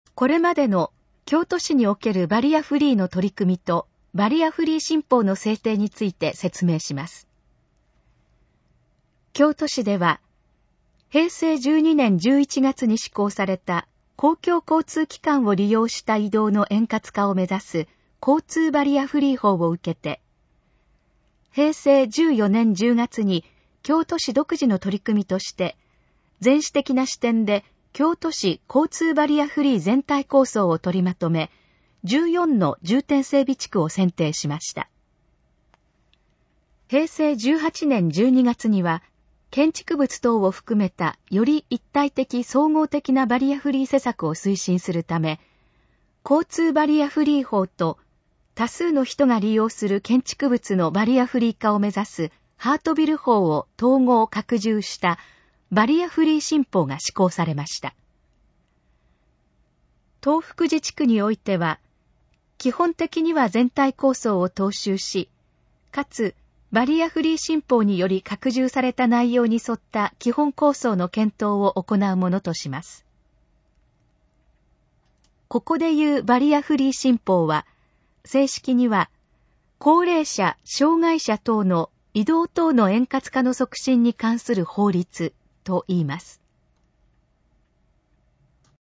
このページの要約を音声で読み上げます。
ナレーション再生 約352KB